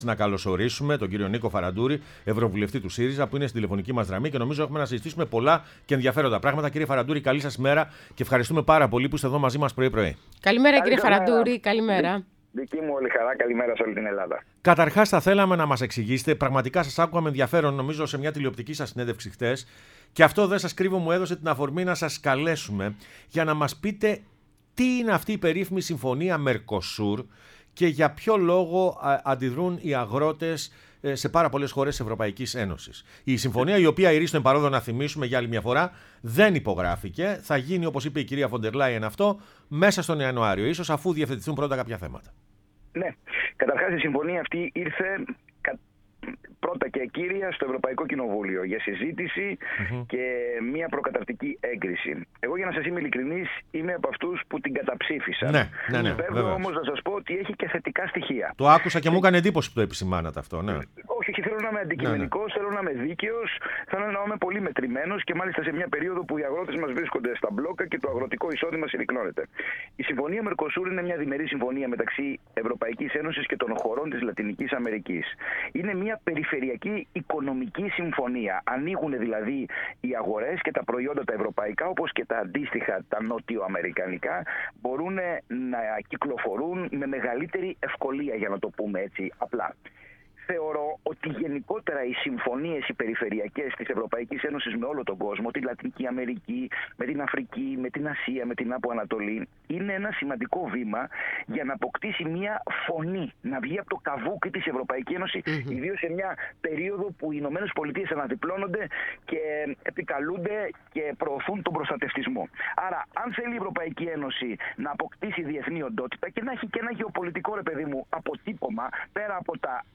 -Ο Ευρωβουλευτής του ΣΥΡΙΖΑ Νίκος Φαραντούρης, μίλησε στην εκπομπή “Πρωινές διαδρομές”